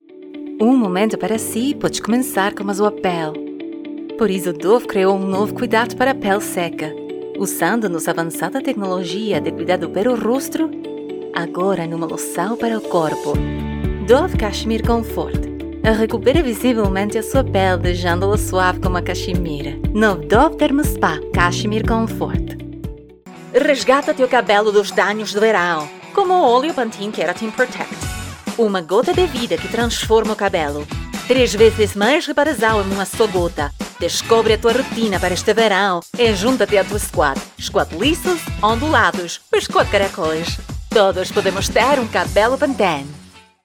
Female
Portuguese Commercial Reel
0630Portuguese_Commercial_Reel.mp3